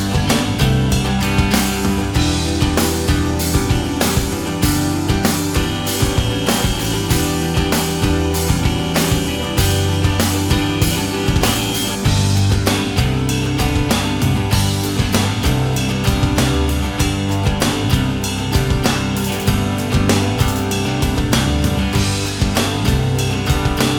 Rock